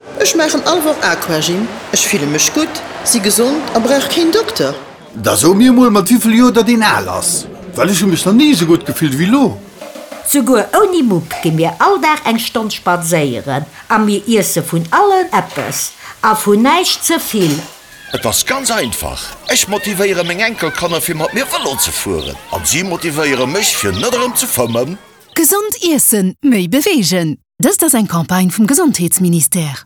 spot-radio-senoirs-gesond-iessen-mei-bewegen-lb.mp3